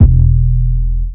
808 (Hell).wav